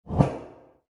Updated a few interface sounds